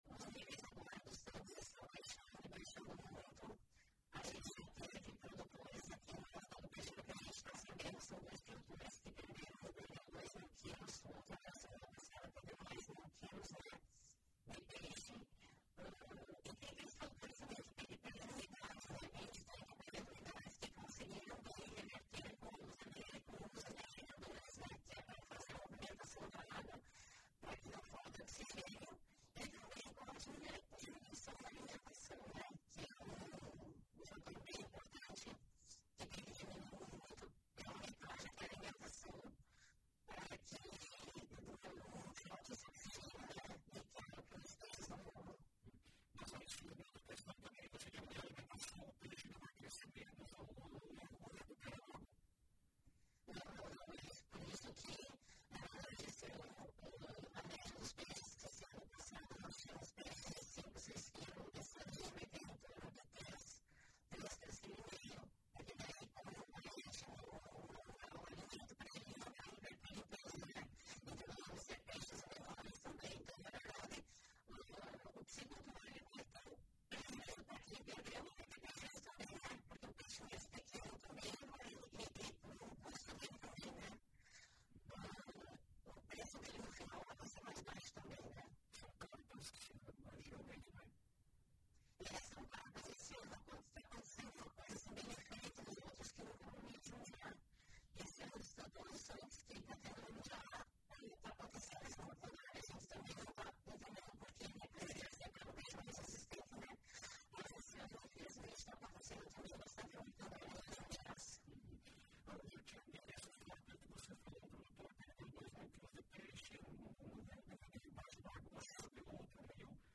Abaixo, entrevista